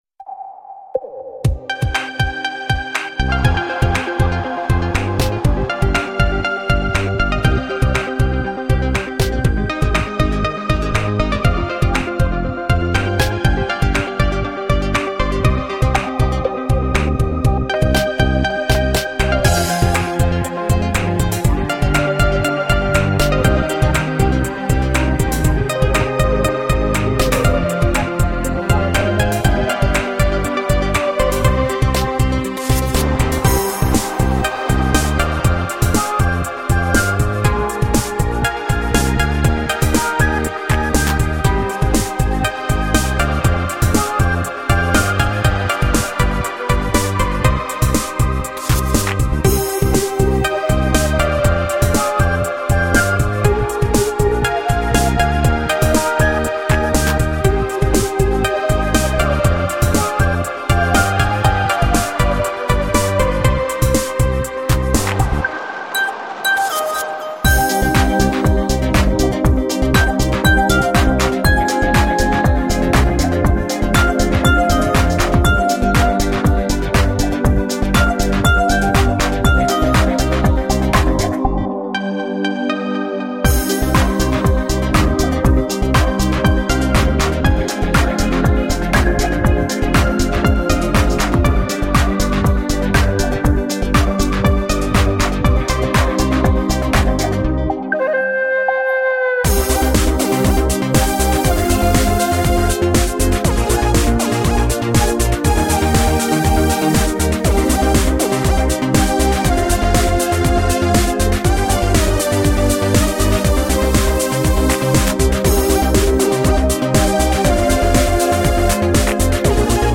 Очень красивый инструментал!